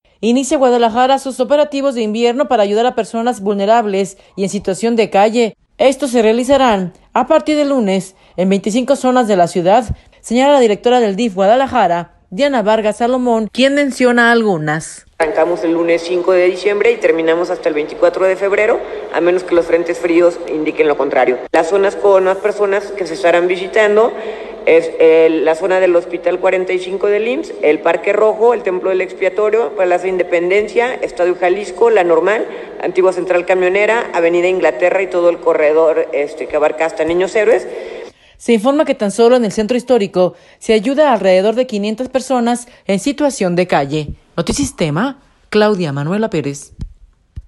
Inicia Guadalajara sus operativos de invierno para ayudar a personas vulnerables y en situación de calle. Estos se realizarán a partir del lunes en 25 zonas de la ciudad, señala la directora del DIF Guadalajara, Diana Vargas Salomón, quien menciona algunas.